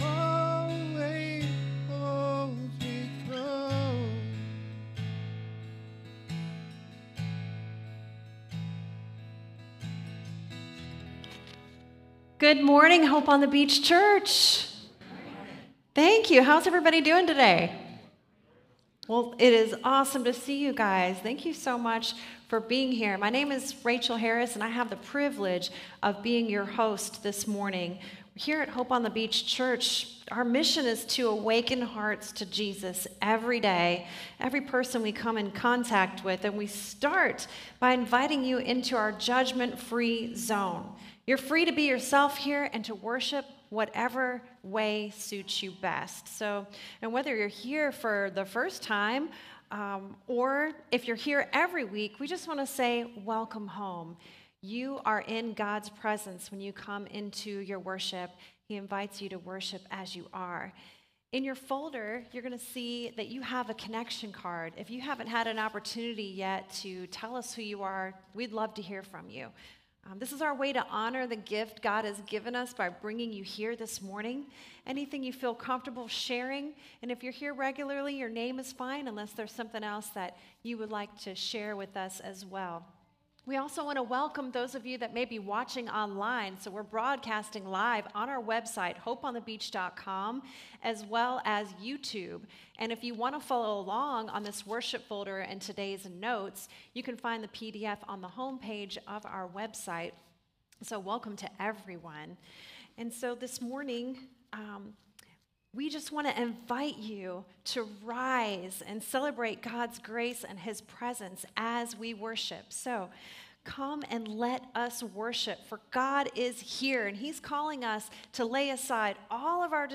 SERMON DESCRIPTION Despite his blindness and the crowd’s discouragement, Bartimaeus called out to Jesus in faith—and was heard, healed, and called to follow.